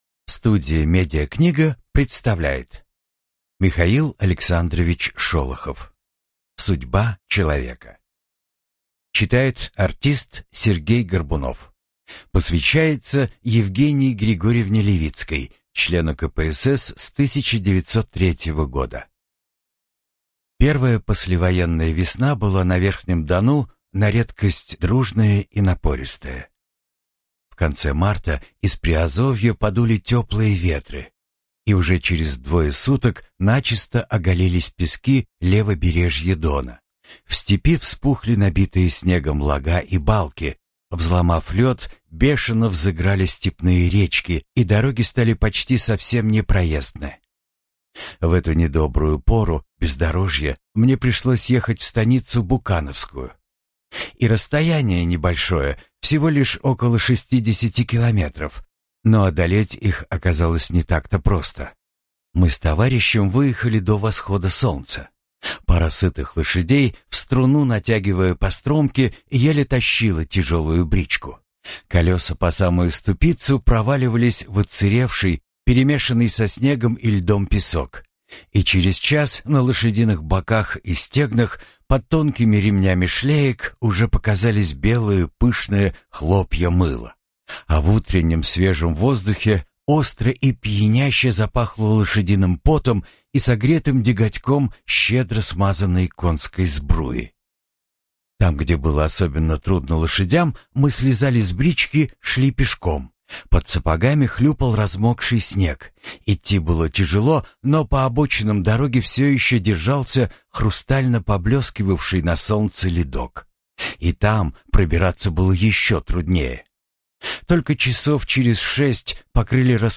Аудиокнига Судьба человека | Библиотека аудиокниг
Прослушать и бесплатно скачать фрагмент аудиокниги